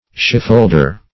Shipholder \Ship"hold`er\, n.